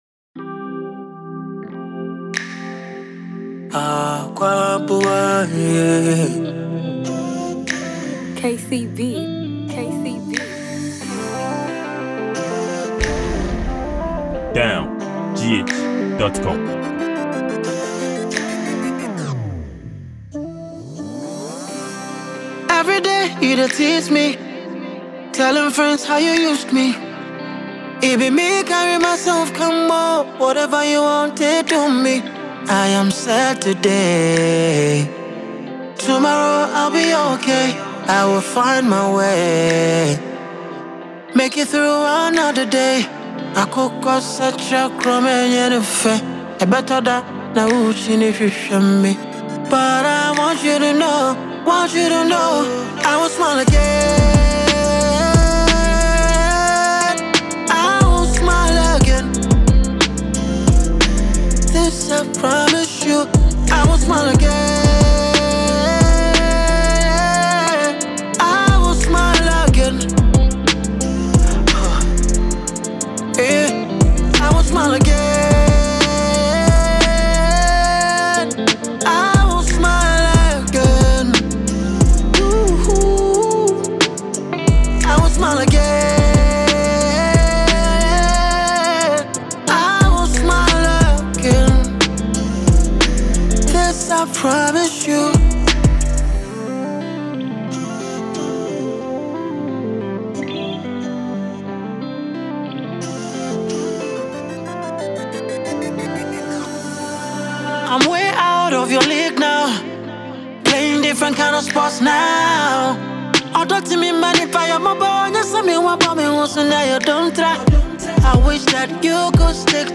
Ghanaian highlife songwriter, singer and musician
heart-touching tune